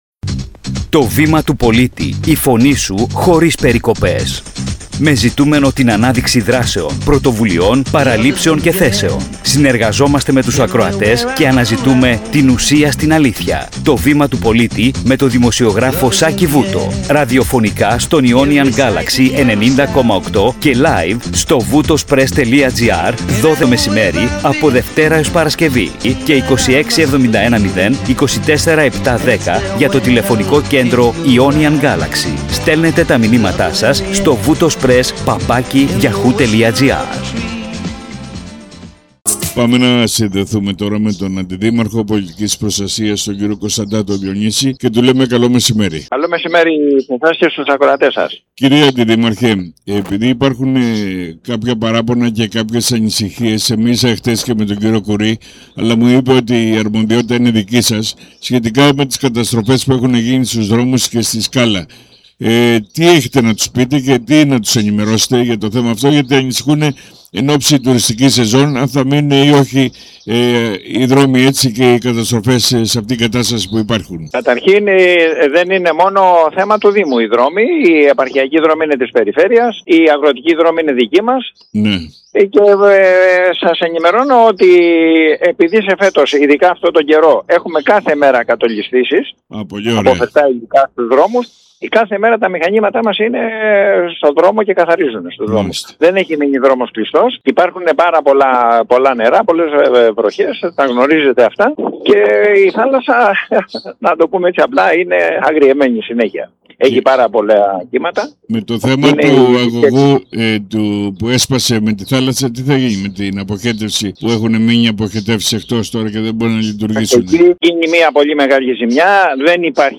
Σημαντικά ζητήματα που αφορούν τις καταστροφές στο οδικό δίκτυο, τη διάβρωση στη Σκάλα και την αντιπλημμυρική θωράκιση σε Περατάτα και κρανιά τέθηκαν στη διάρκεια της εκπομπής «Το Βήμα του Πολίτη» στον Ionian Galaxy 90.8, με καλεσμένο τον Αντιδήμαρχο Πολιτικής Προστασίας Διονύση Κωνσταντάτο.